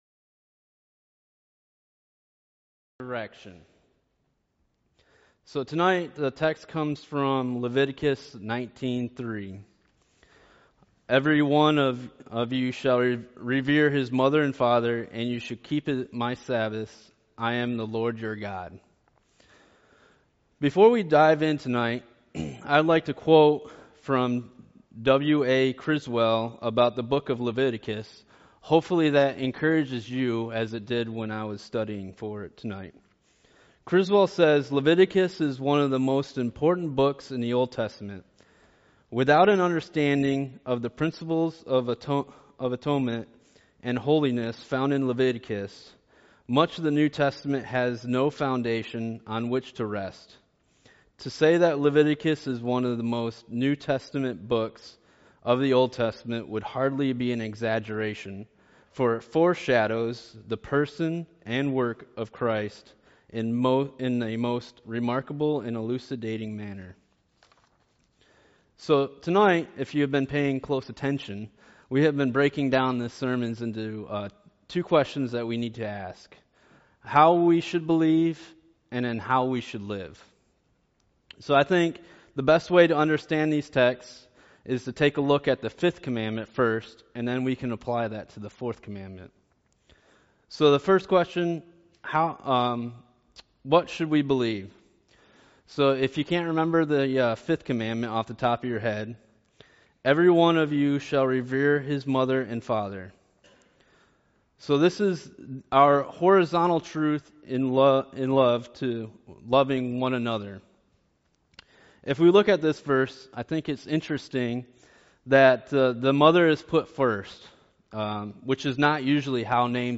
Sunday Evening Preachers Training | Poquoson Baptist Church